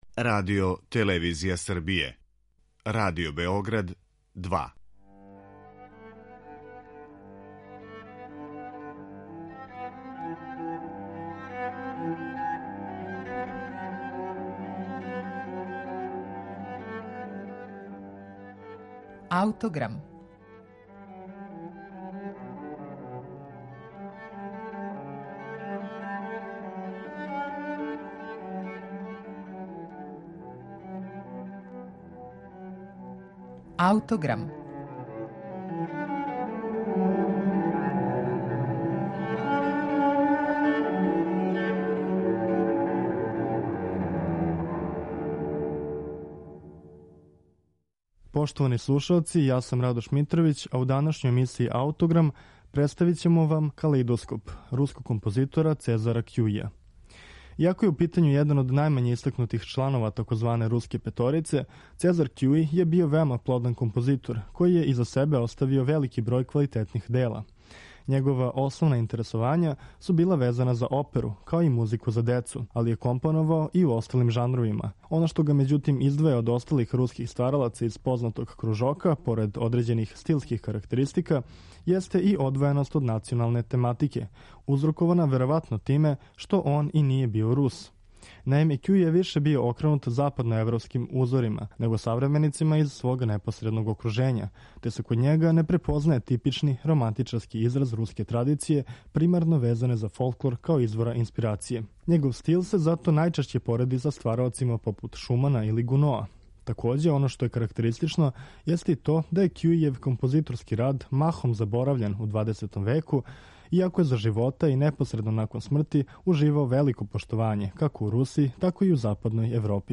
Таква судбина се везује и за збирку 24 минијатура за виолину и клавир - Калеидоскоп оп. 50 настала 1894. године.